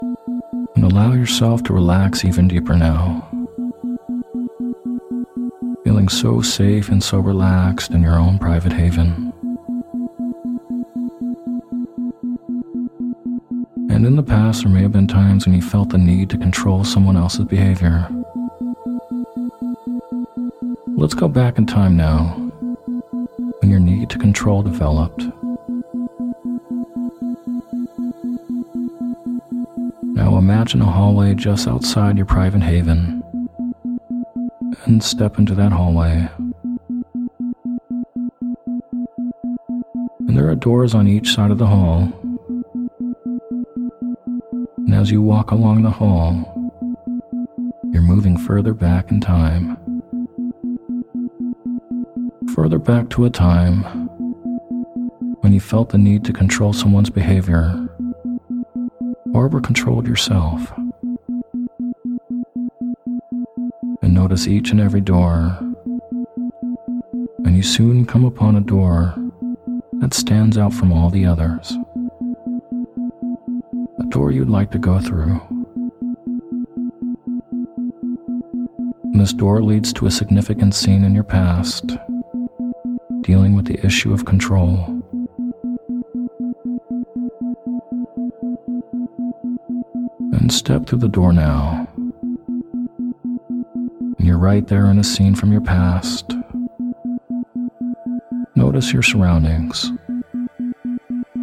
Sleep Hypnosis For Releasing Control With Isochronic Tones